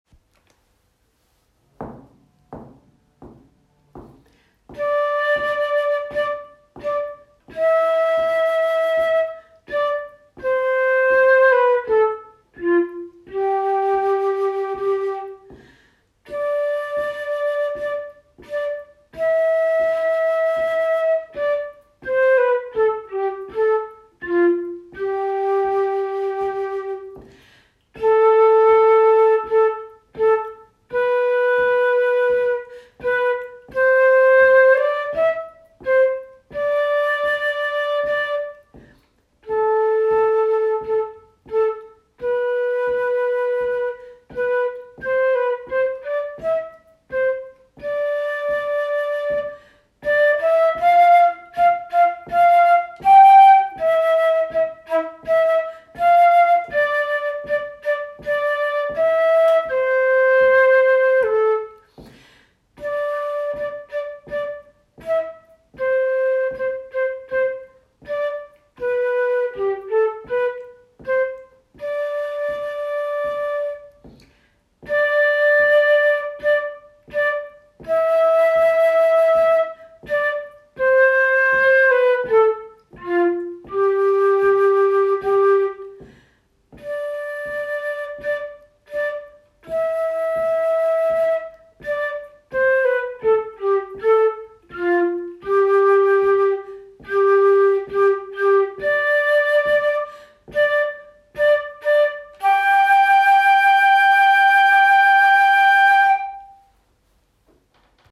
Practice speed